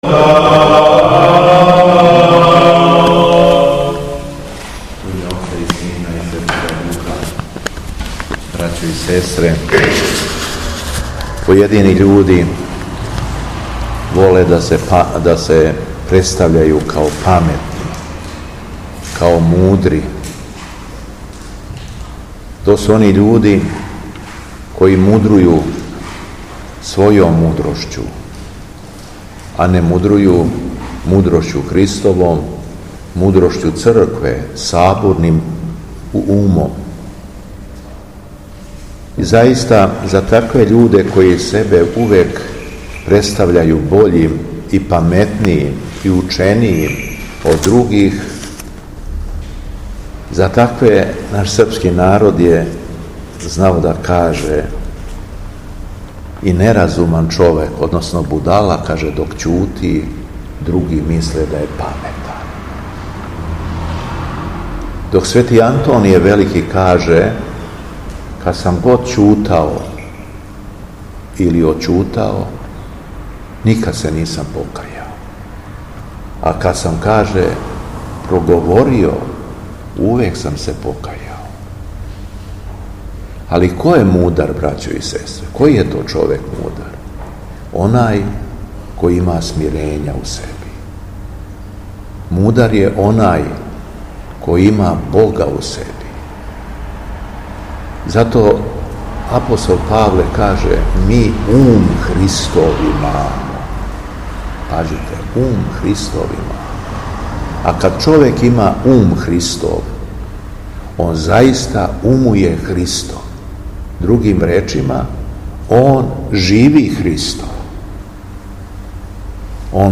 Беседа Његовог Високопреосвештенства Митрополита шумадијског г. Јована
Након прочитаног јеванђељске перикопе верном народу се надахнутим словом обратио Митрополит Јован: